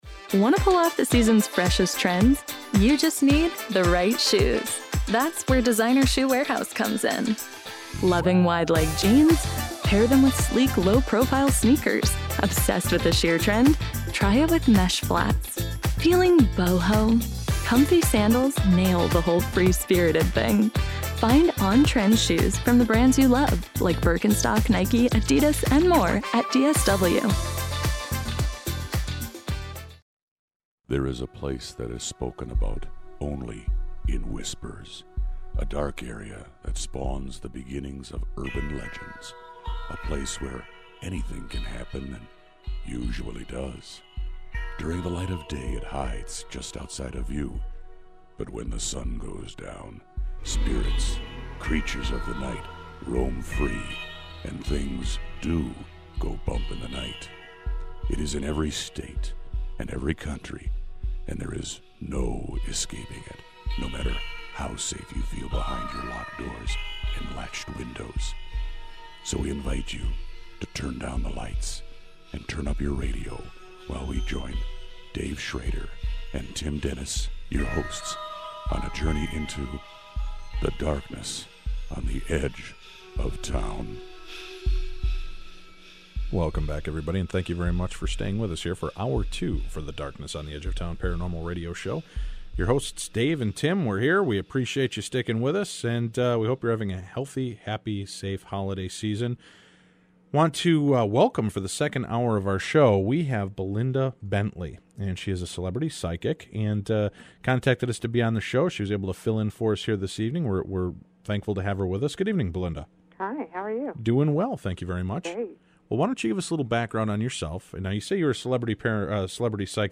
Paranormal Radio Show